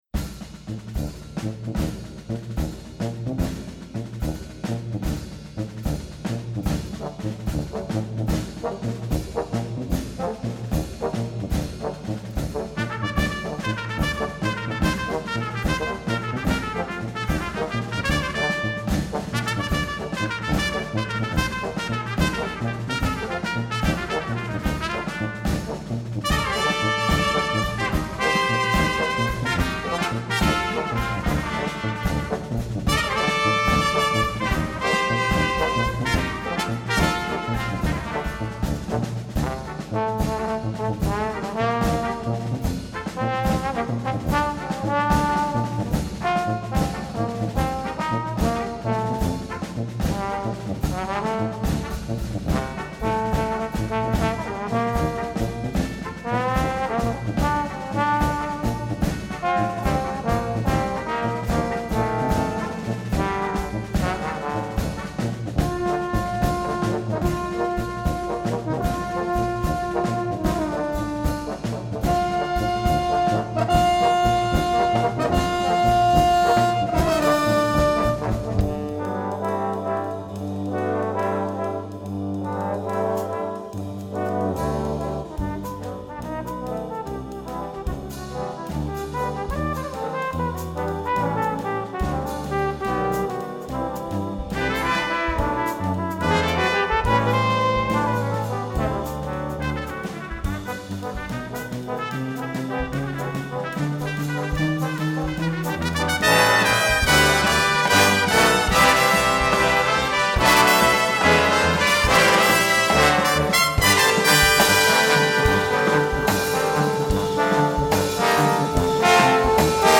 FOR WINDS
4 trumpets, 4 trombones, french horn, tuba